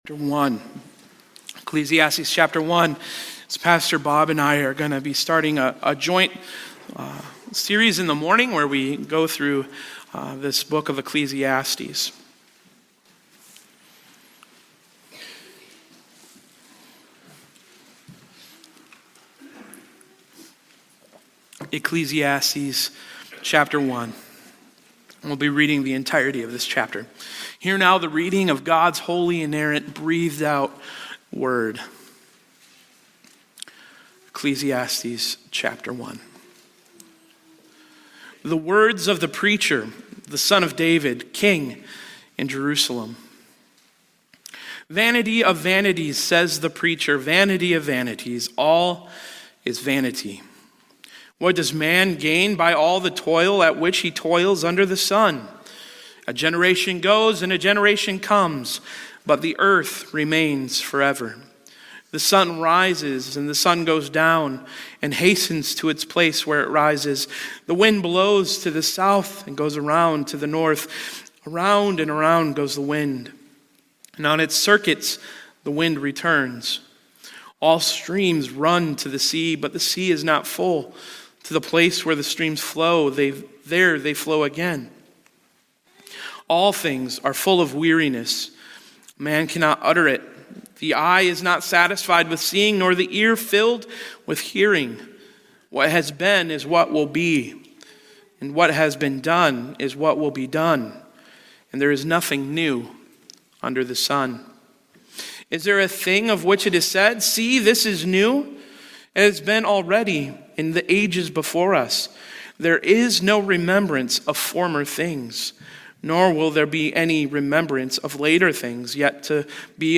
Busily Chasing After the Wind | SermonAudio Broadcaster is Live View the Live Stream Share this sermon Disabled by adblocker Copy URL Copied!
Little Farms Chapel OPC